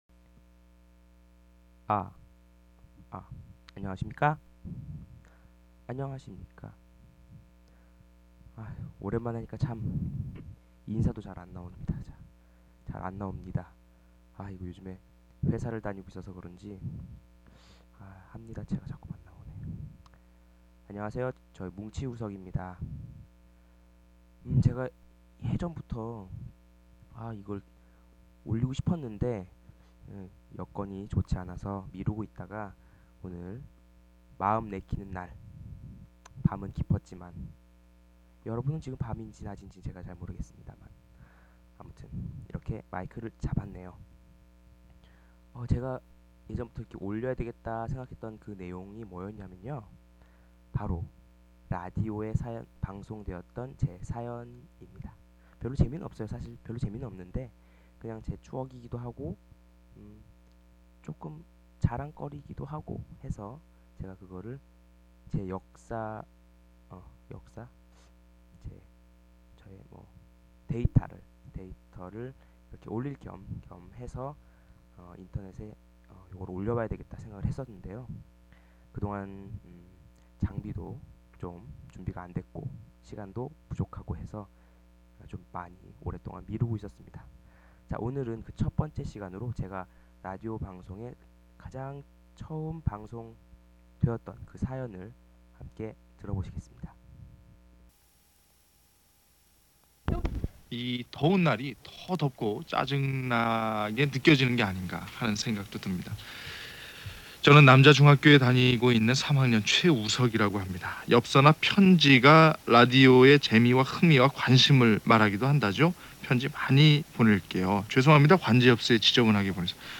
볼륨조절이 잘 되지 않았네요. 너무 속삭인 거 같아요.